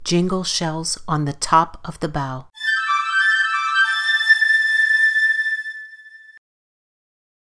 Jingle Shells on the bow – 7_15_25, 9.34 AM 1